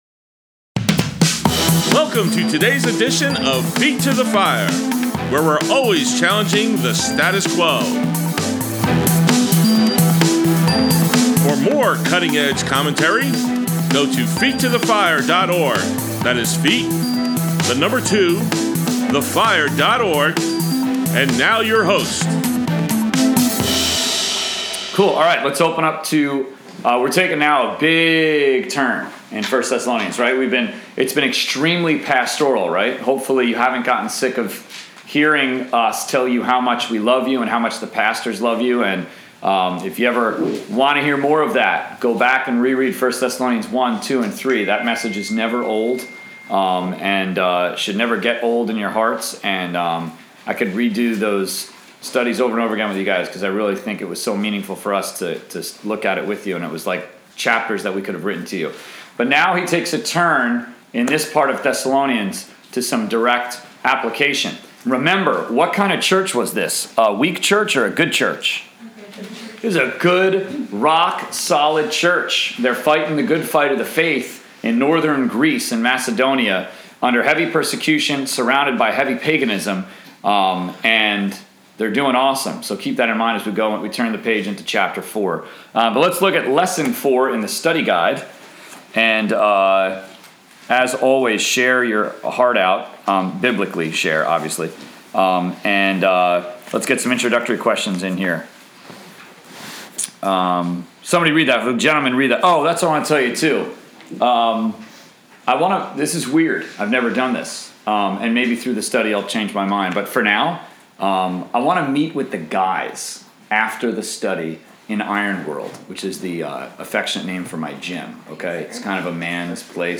College/Career Group Bible Study, February 4, 2017, Part 1 of 2